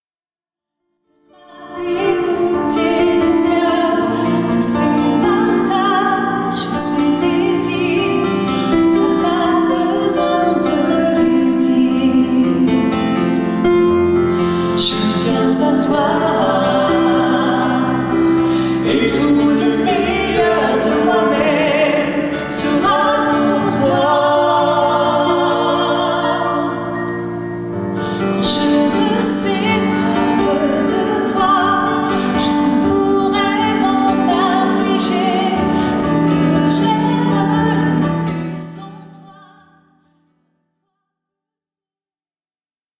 live version